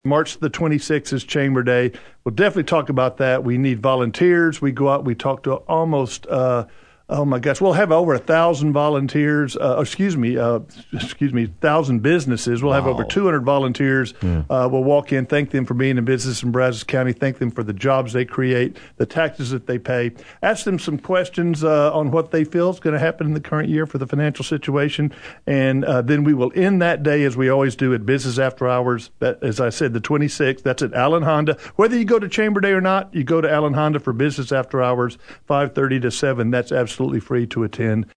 comments on WTAW’s The Infomaniacs